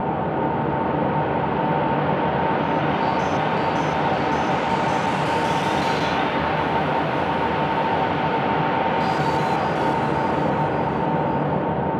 Index of /DESN275/loops/Loop Set - Aerosol - Ambient Synth Loops - F and Dm/Loops
CrashingFeeling_80_Texture.wav